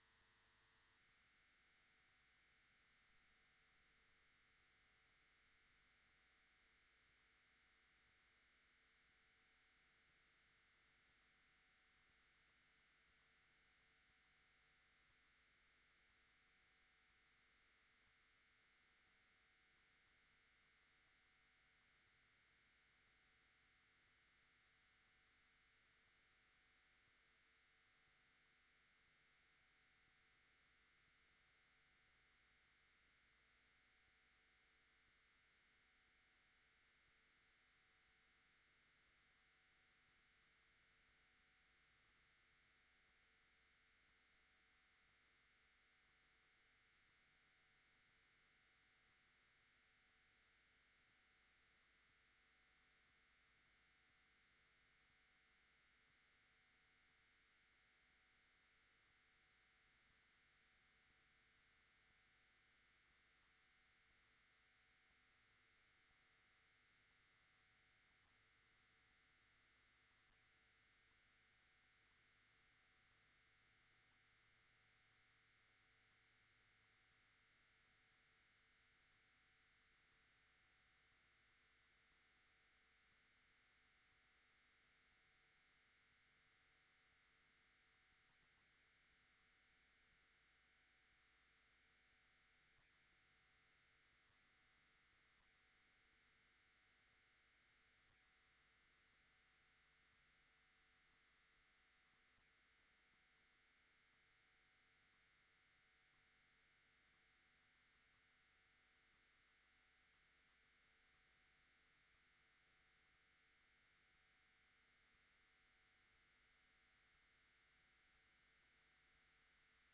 26 March 2025 Ordinary Meeting Lachlan Shire Council
The March 2025 meeting will be held in the Council Chambers at 2:00pm and is open to the public.
march-council-meeting-recording.mp3